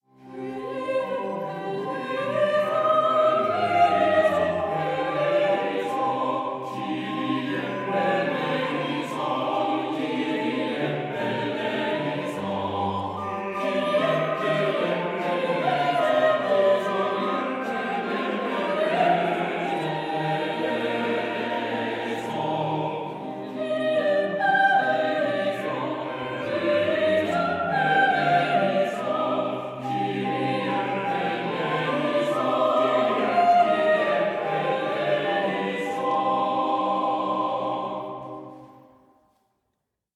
Stereo
organ solo
harpsichord